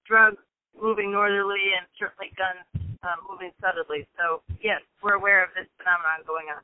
NORTHAMPTON, MASSACHUSETTS POLICE CHIEF JODY KASPER SAYS LAW ENFORCEMENT IS VERY AWARE OF THE BLACK MARKET TRADE BETWEEN VERMONT AND MASSACHUSETTS IN GUNS FOR DRUGS.